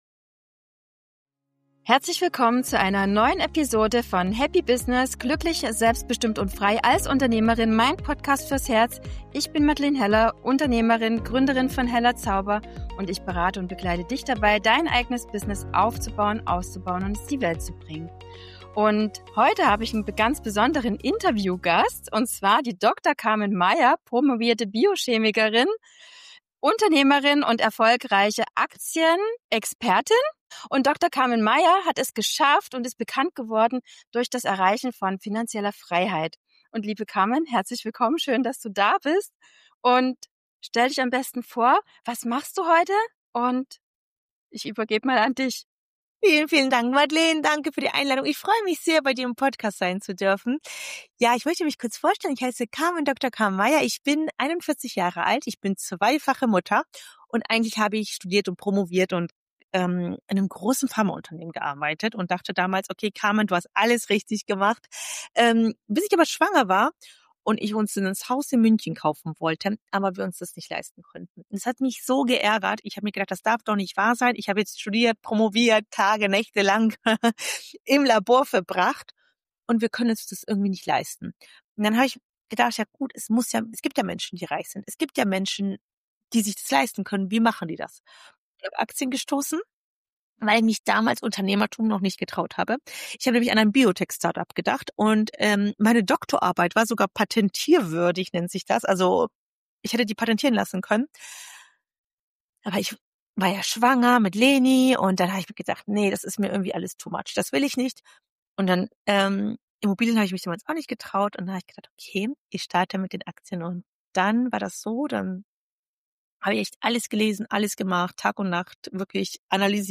Geld-Mindset für Unternehmerfrauen: Dein Weg in die finanzielle Unabhängigkeit (Interview